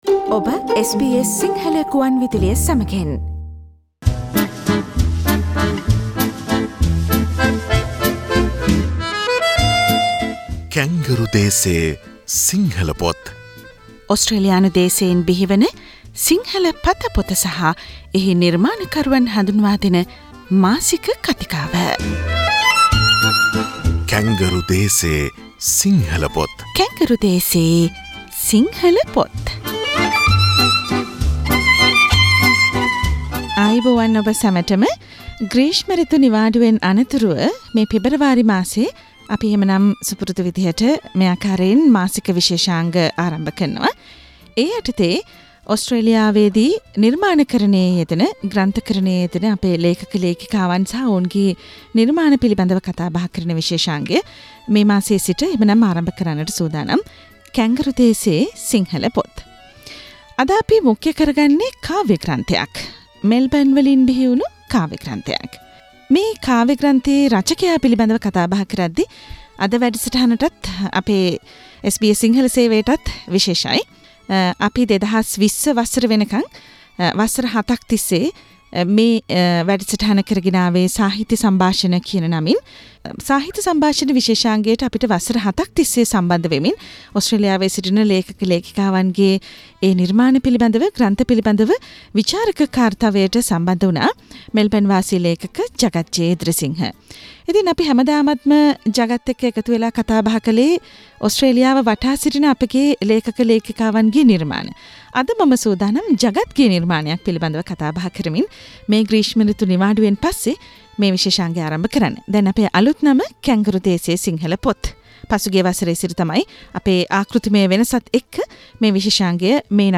ඕස්ට්‍රේලියානු දේශයෙන් බිහිවන සිංහල පතපොත සහ එහි නිර්මාණ කරුවන් හඳුන්වාදෙන SBS සිංහල සේවයේ මාසික පිළිසඳර: "කැන්ගරු දේසේ සිංහල පොත් "